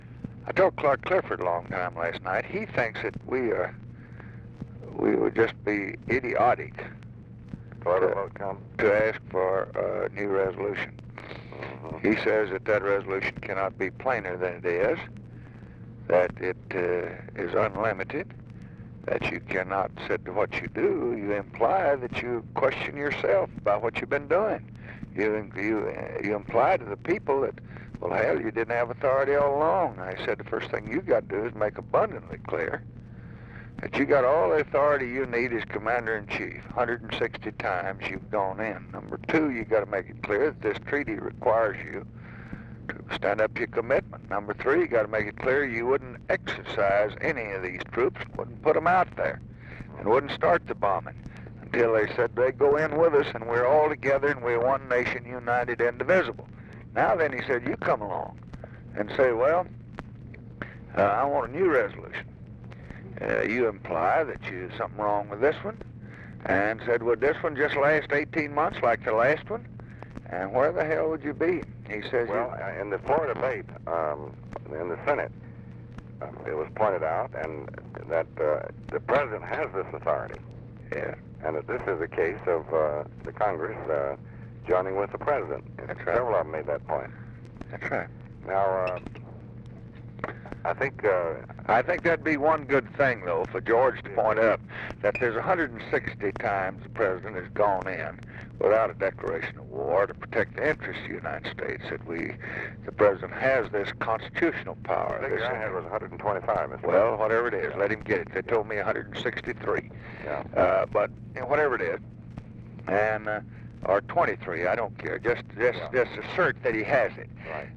Location: Mansion